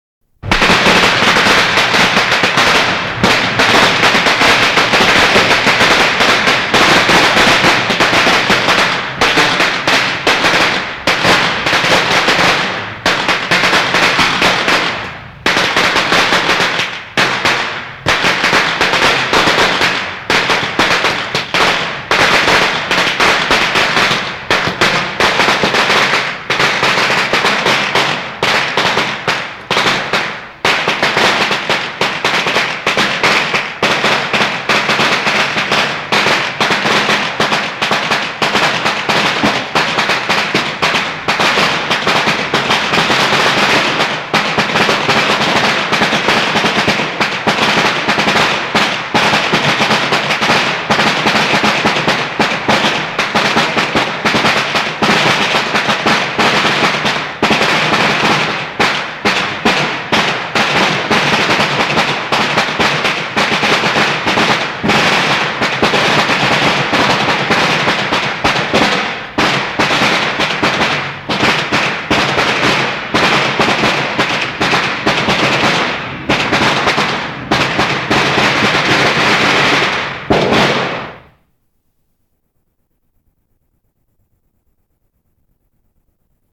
均为CD直接抓取，效果极佳。 试听段为192k MP3格式，效果不好。